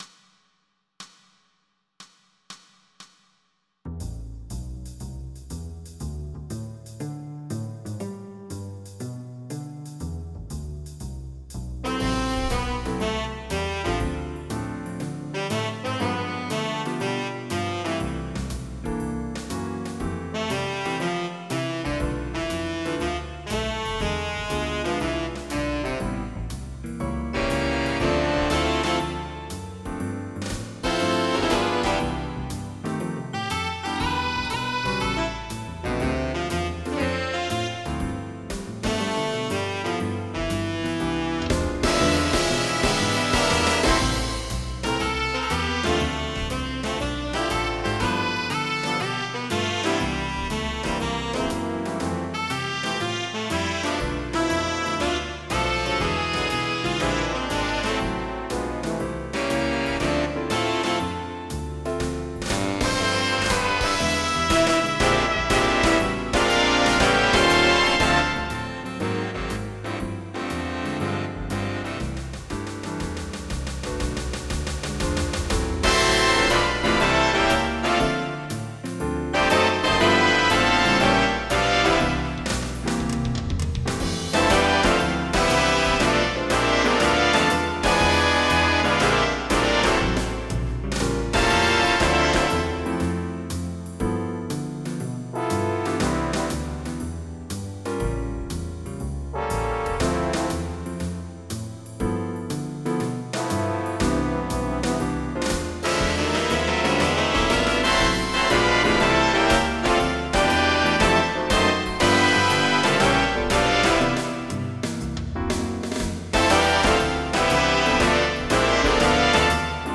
Jazz
MIDI Music File
Type General MIDI